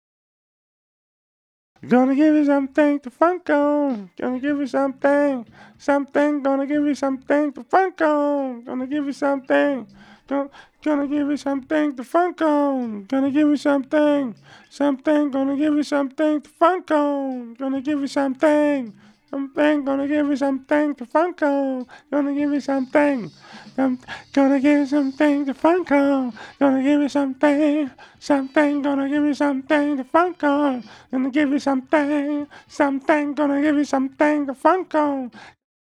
DF_107_E_FUNK_VOX_05 .wav